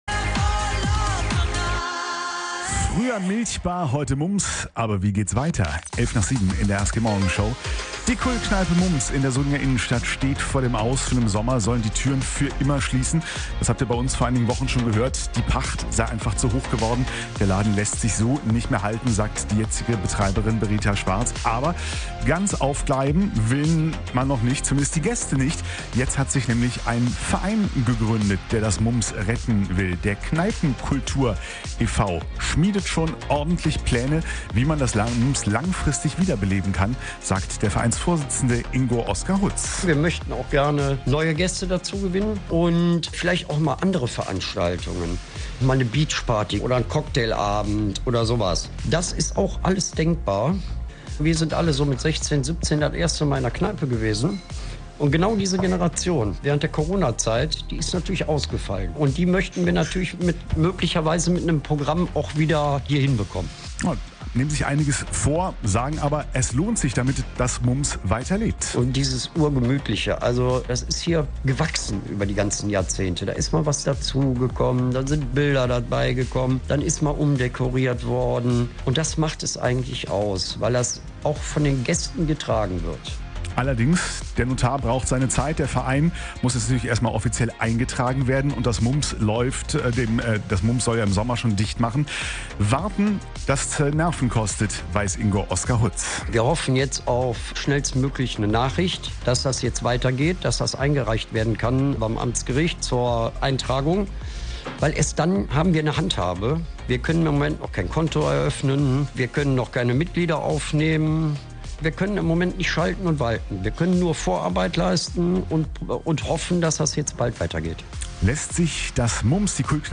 berichtet in der Morgenshow vom aktuellen Stand, den weiteren Plänen und noch bestehenden Hürden.